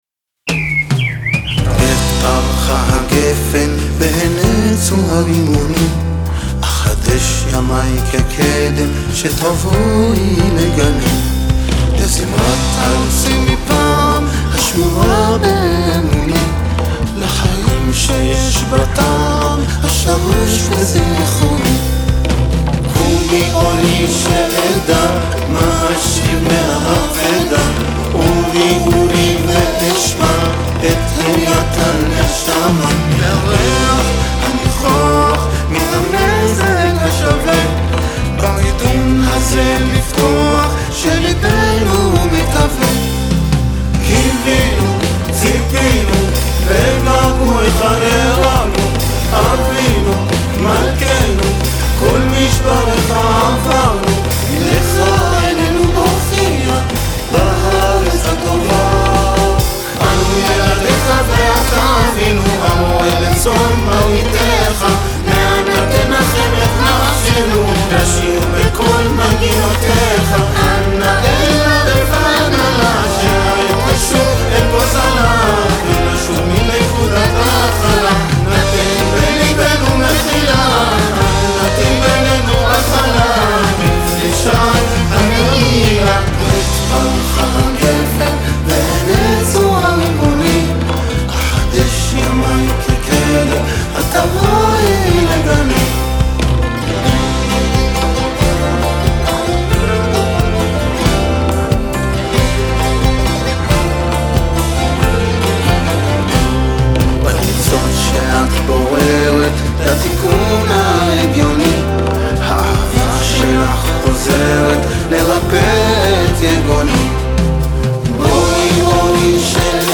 הלחן פרסי
בחלקו של השיר תוכלו לשמוע חלק מהמילים המקוריות בפרסית.